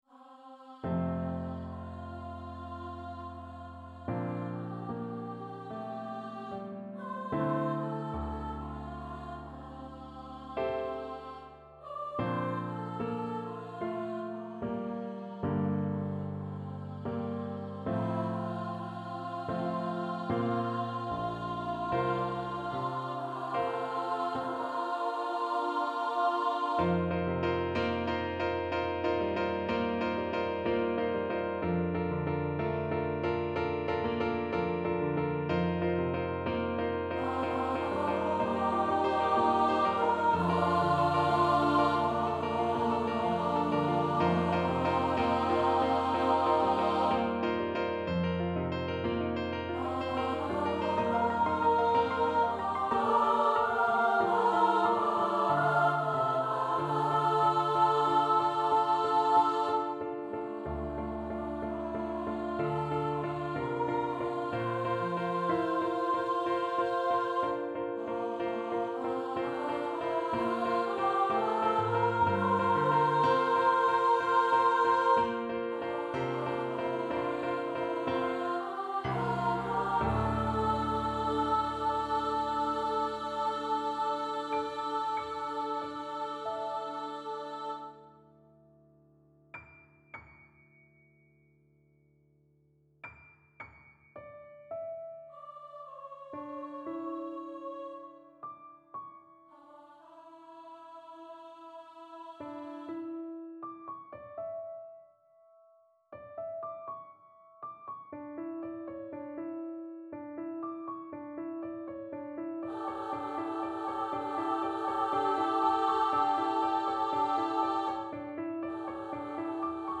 (Demo recording).
A highly atmospheric work
SSAA, piano